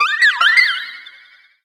Catégorie:Cri Pokémon (Soleil et Lune) Catégorie:Cri de Guérilande